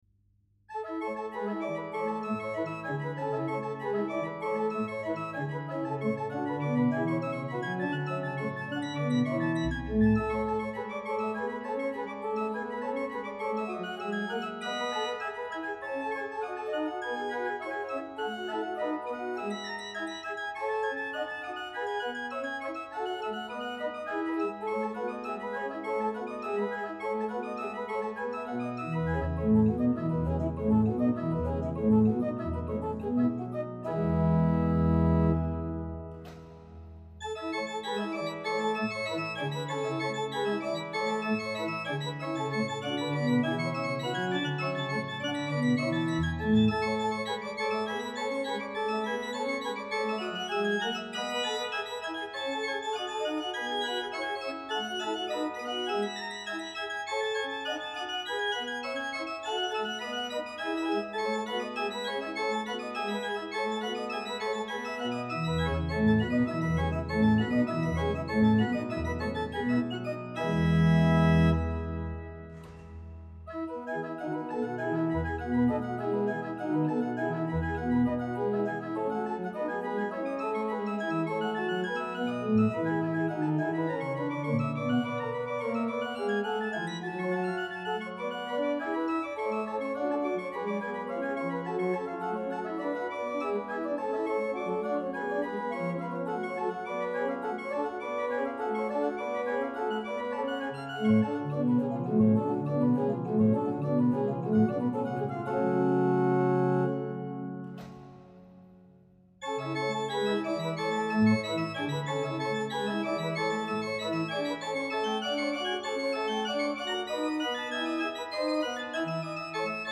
No additional effect processing have been added to the recording. The tail of release is the original of the samples. All traks were recorded with the original temperament of the organ with the exeption of tracks by J.S.Bach that were recorded with equal temperament..
a) Principale + Ottava
b) Principale + Ottava + XV + XXII